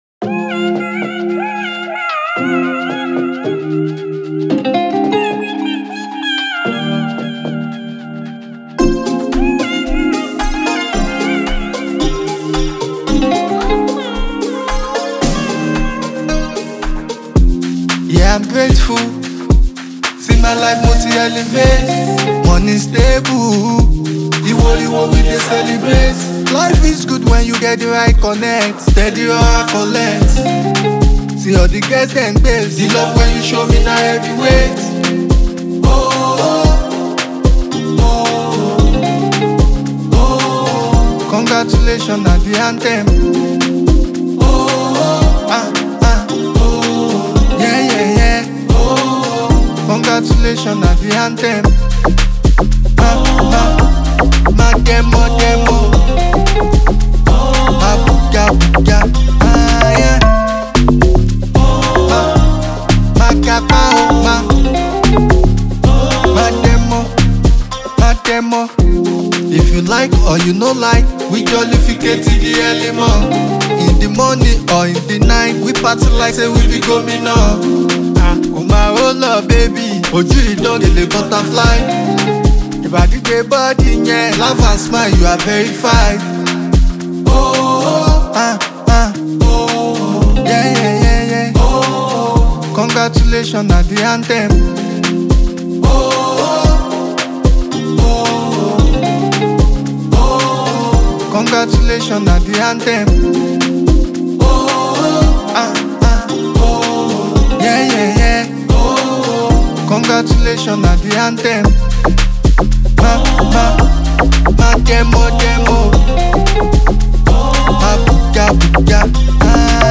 a raw and melodic anthem that embodies the hustle